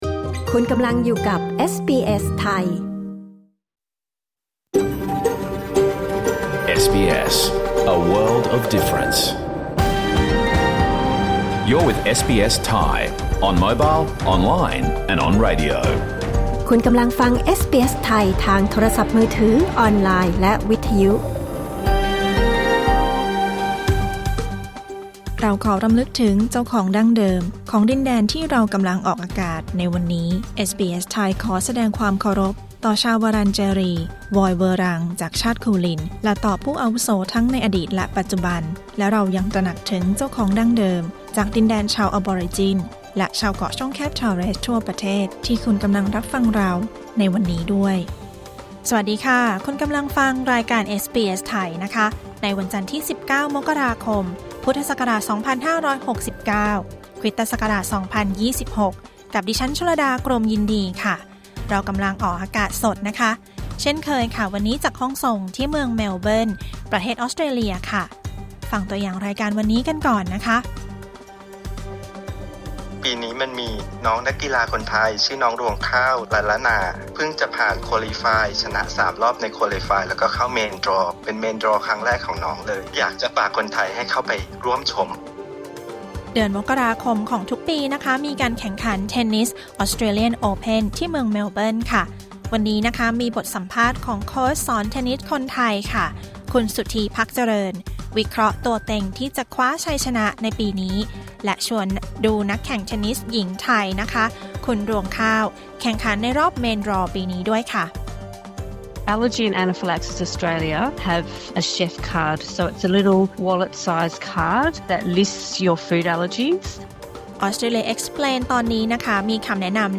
รายการสด 19 มกราคม 2569